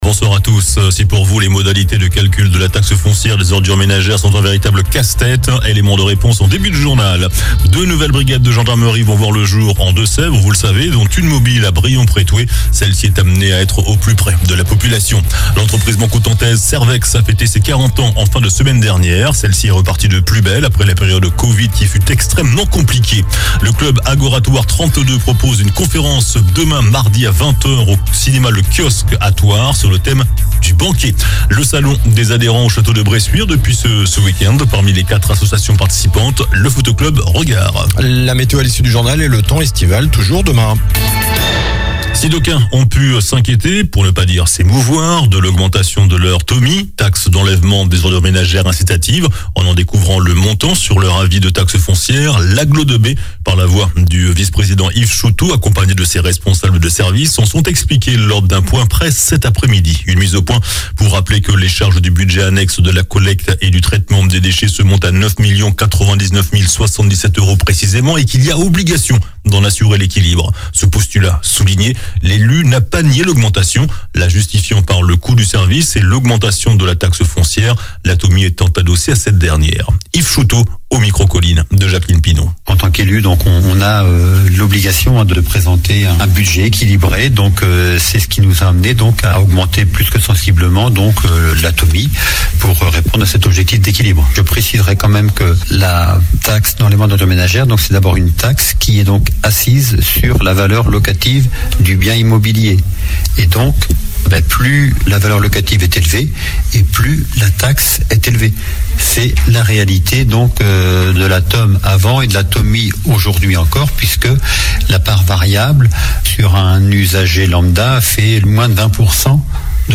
JOURNAL DU LUNDI 09 OCTOBRE ( SOIR )